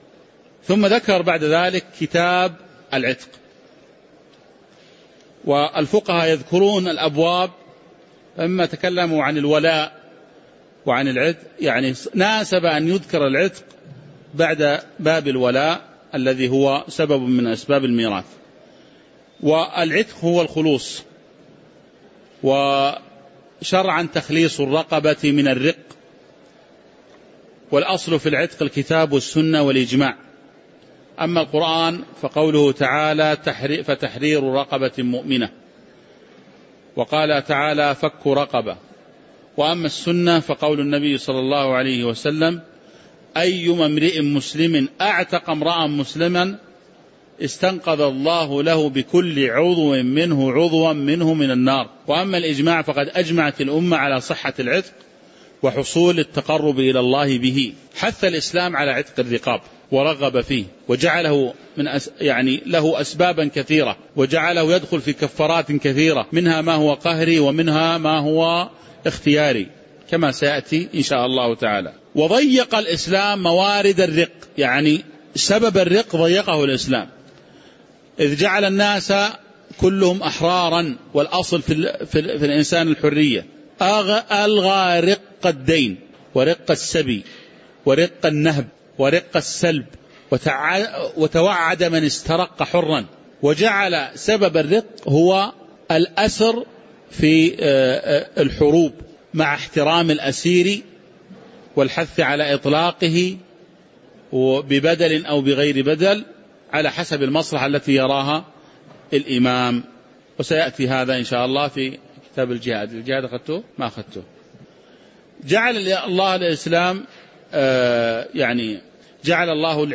تاريخ النشر ٢٠ شوال ١٤٣٩ هـ المكان: المسجد النبوي الشيخ